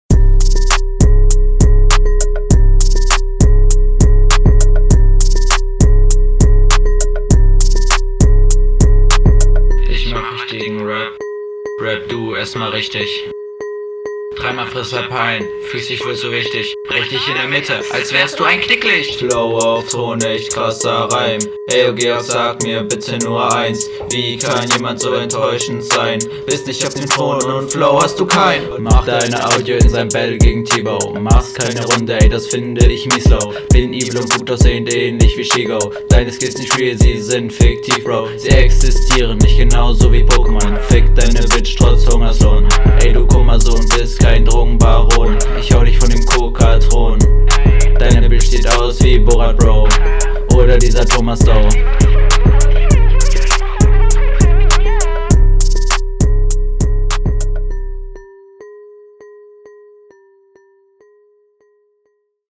Flow: flow ist mir etwas zu monoton aber ich höre keine Fehler raus Text: paar …
Flow: Wirkt etwas gelangweilt, trifft aber den Takt Text: Reime kritisieren aber Reim, eins, sein, …